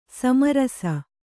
♪ sama rasa